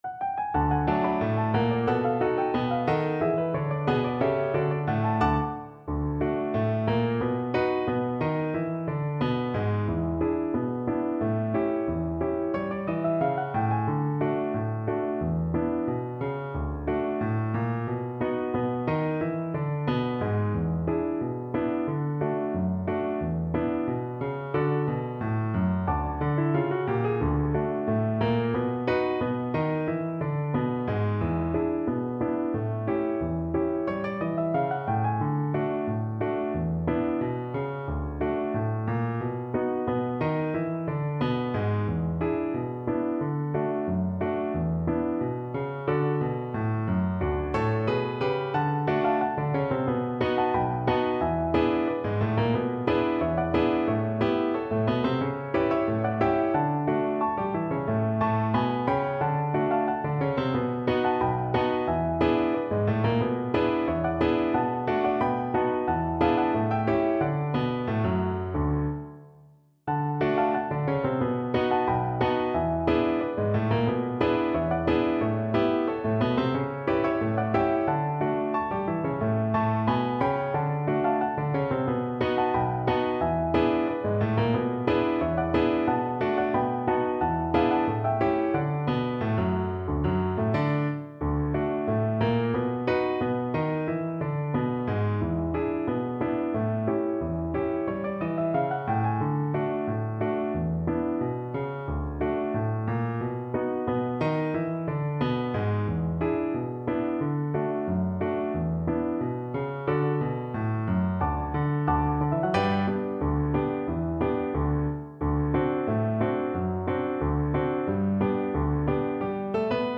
Free Sheet music for Violin
Violin
D major (Sounding Pitch) (View more D major Music for Violin )
Slow Drag. = 90
2/4 (View more 2/4 Music)
Jazz (View more Jazz Violin Music)